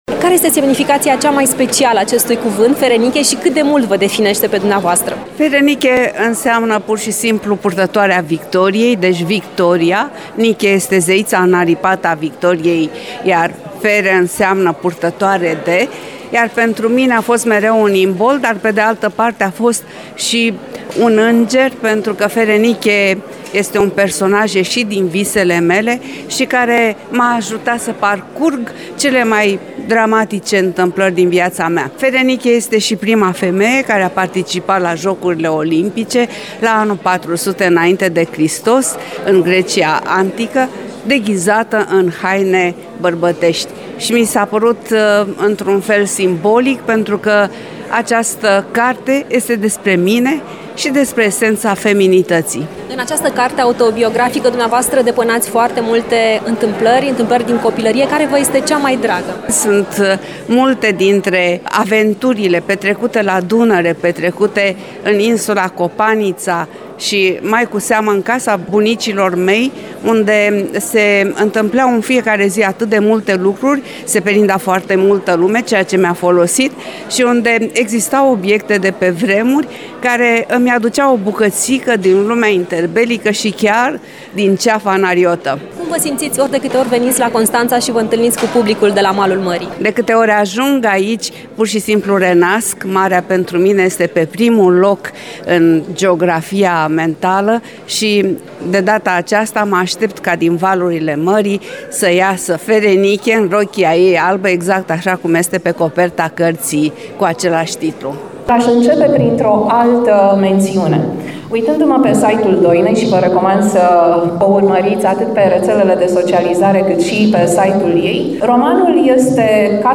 AUDIO | Scriitoarea Doina Ruști, invitată la „Povești din Dobrogea” - Știri Constanța - Radio Constanța - Știri Tulcea
Prima pagină » Ştiri » Cultură » AUDIO | Scriitoarea Doina Ruști, invitată la „Povești din Dobrogea” AUDIO | Scriitoarea Doina Ruști, invitată la „Povești din Dobrogea” Zilele trecute, Doina Ruști și-a lansat romanul autobiografic intitulat FERENIKE, la un eveniment găzduit de Biblioteca Județeană Constanța.